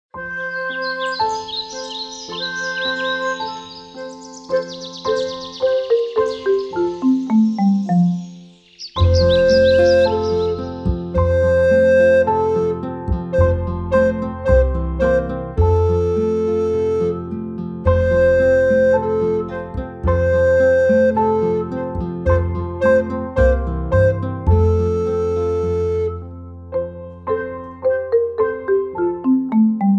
Besetzung: Sopranblockflöte